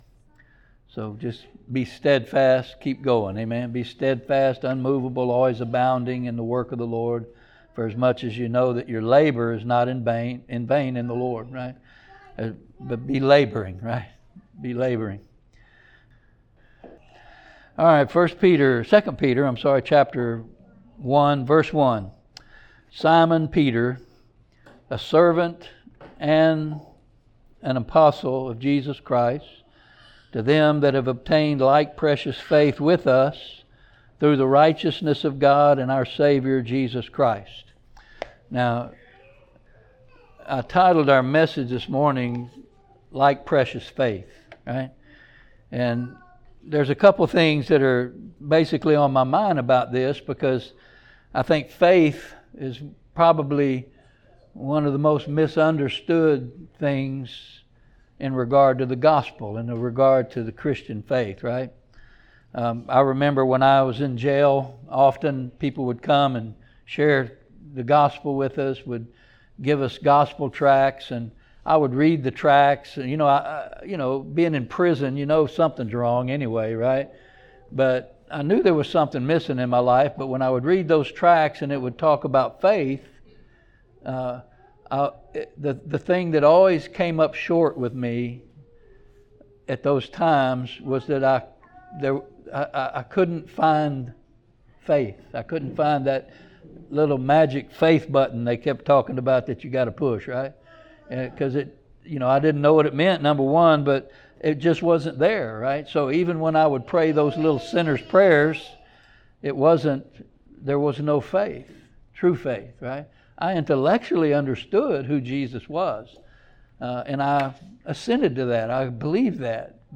2 Peter 1:1 Service Type: Sunday Morning Topics